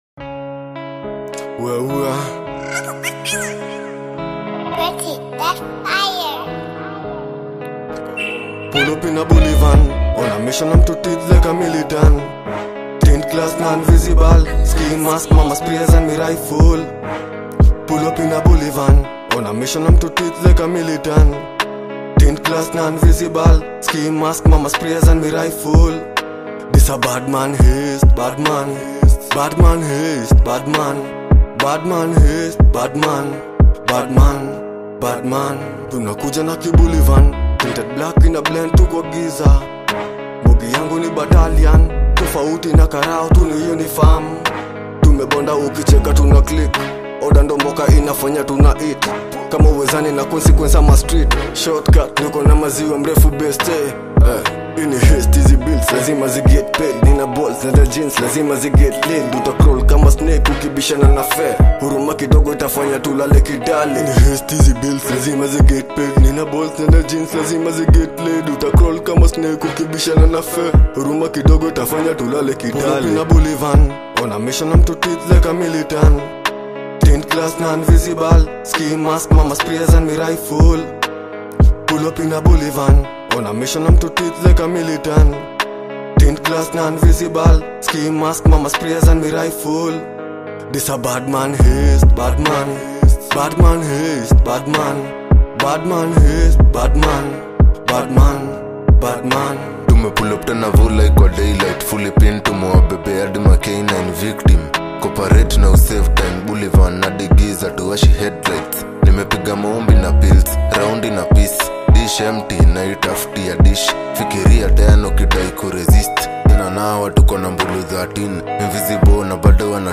With its gritty vibe and powerful rhythm